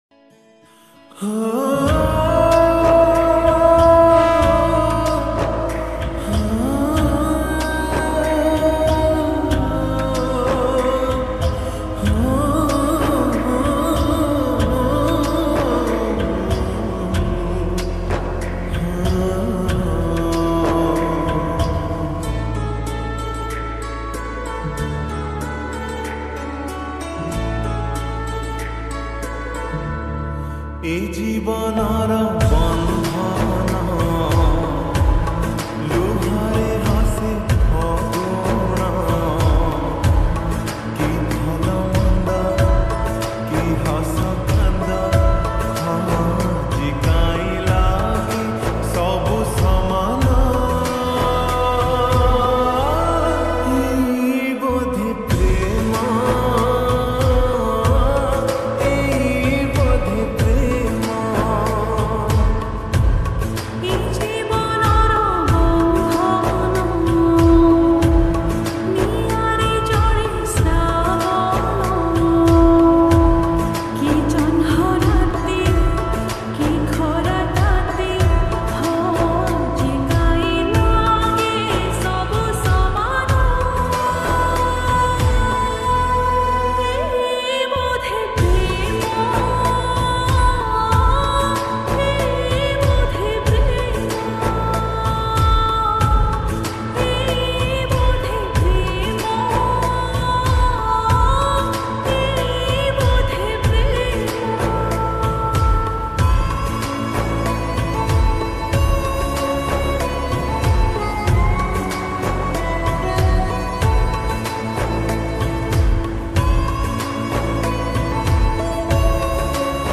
odia lofi song Duration